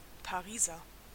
Ääntäminen
France: IPA: [œ̃ pʁe.zɛʁ.va.tif]